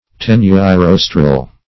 Search Result for " tenuirostral" : The Collaborative International Dictionary of English v.0.48: Tenuirostral \Ten`u*i*ros"tral\, a. (Zool.)